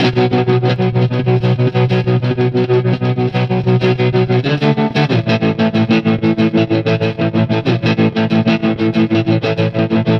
Trem Trance Guitar 01g.wav